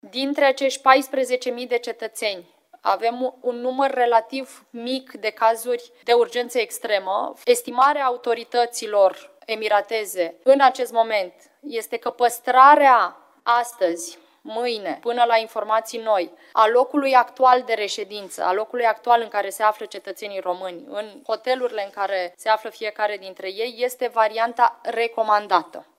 Ministra de Externe, Oana Țoiu: „Dintre acești 14.000 de cetățeni, avem un număr relativ mic de cazuri de urgență extremă”